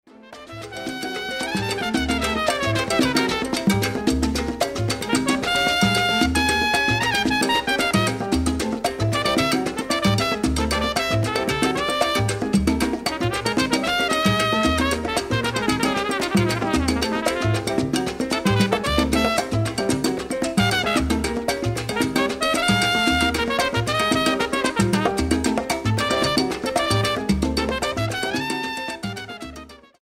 Salsa Charts - Februar 2011